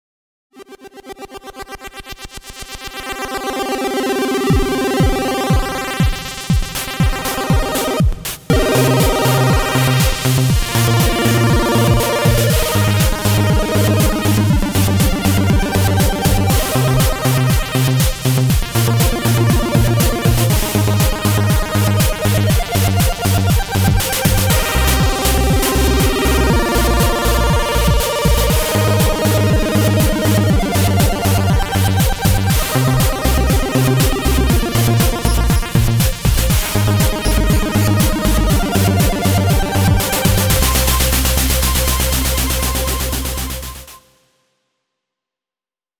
These songs are created with AutoCAD.